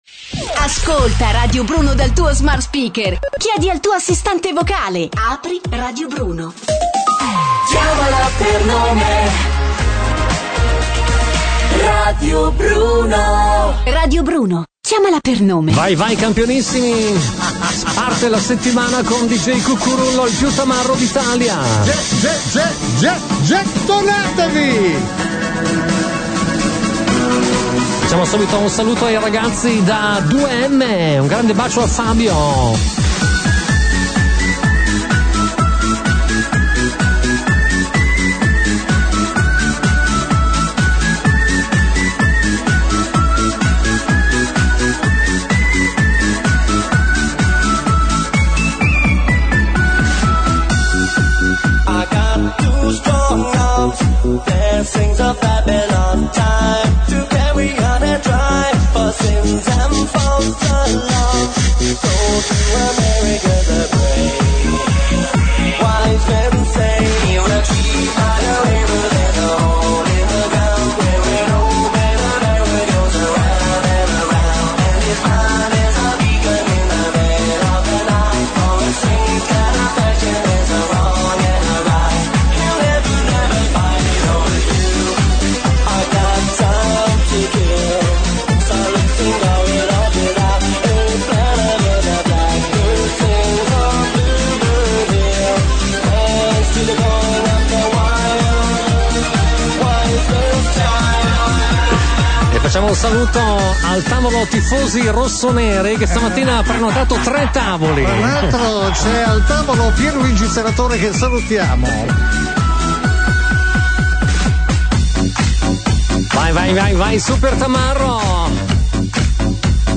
Non possono mancare i commenti degli ascoltatori.